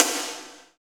47.06 SNR.wav